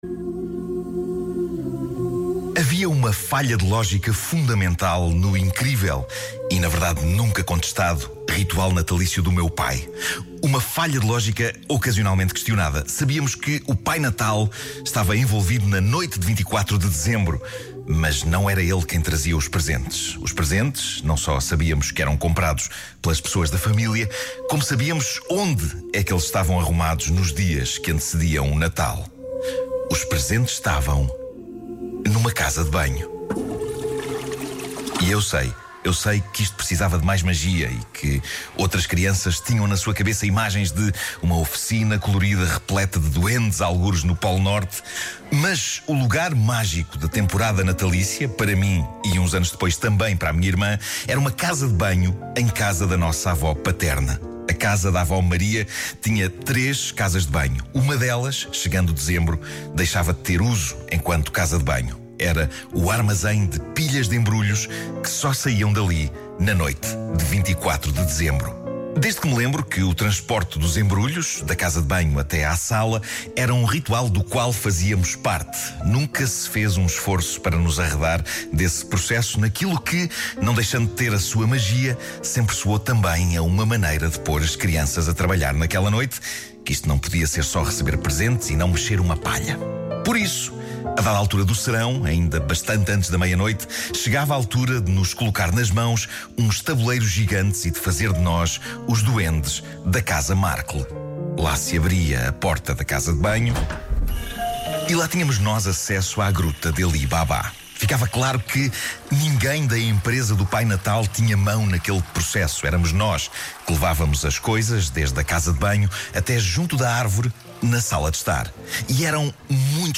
Um conto de Natal contado por Nuno Markl.